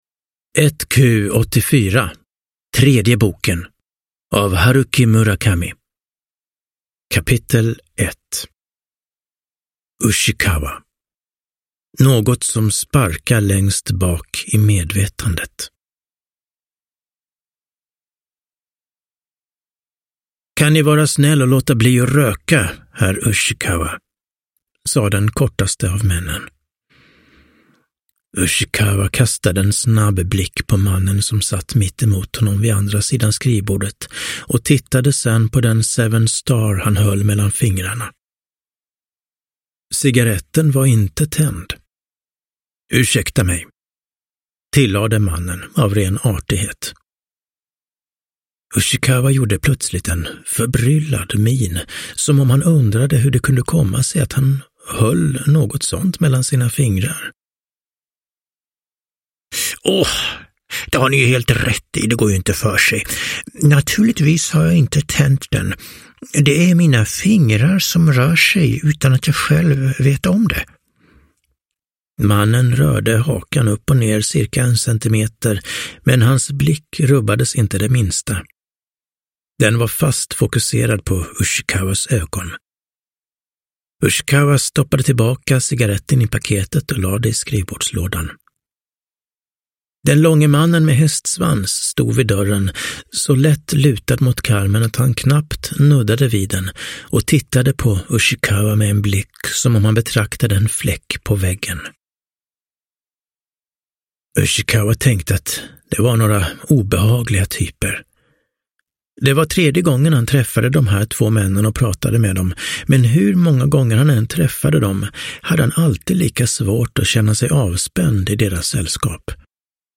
1Q84 : tredje boken – Ljudbok – Laddas ner